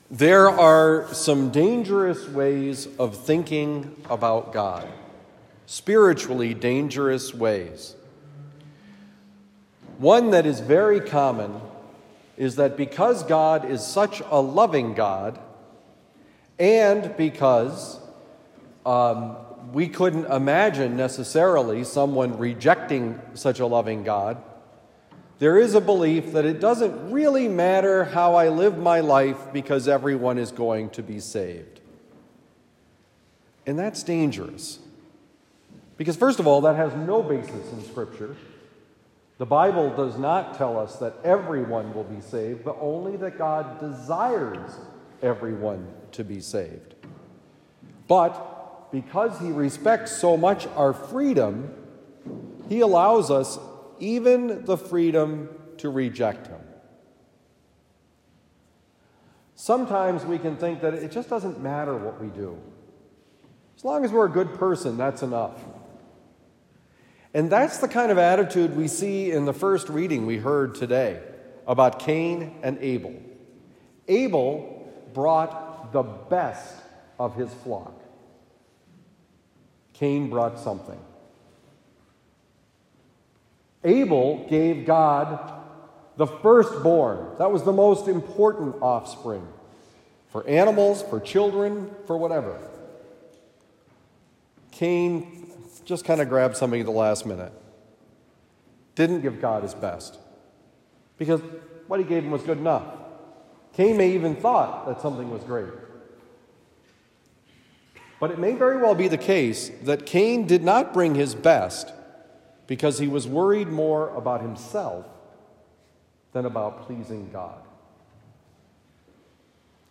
Give God Your All: It Will Not Disappoint: Homily for Monday, February 13, 2023
Given at Christian Brothers College High School, Town and Country, Missouri.